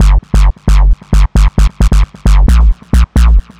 Index of /90_sSampleCDs/Club_Techno/Bass Loops
BASS_133_G.wav